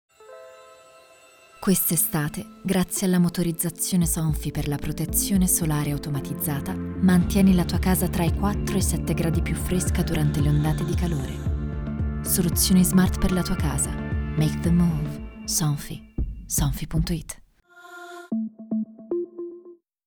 Commerciale, Profonde, Polyvalente, Amicale, Chaude
Corporate